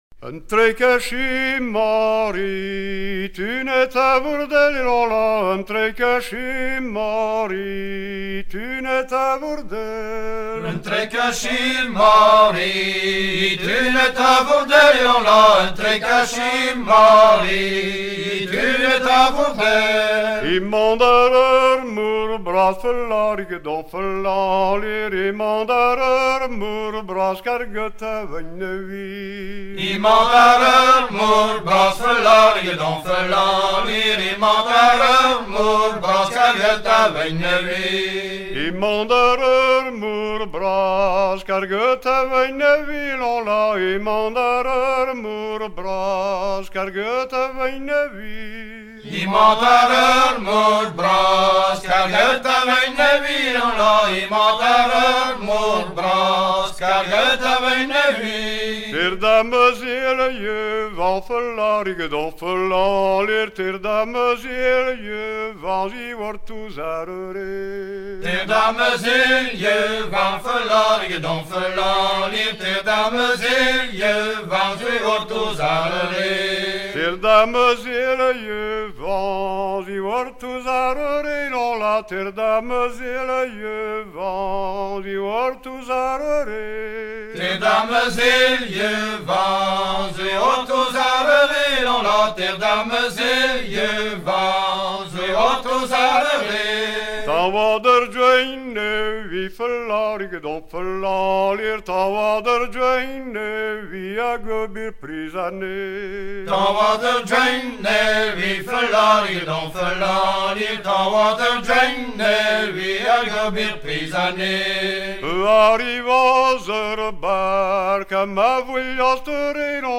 Fonction d'après l'analyste gestuel : à marcher
Genre laisse
Pièce musicale éditée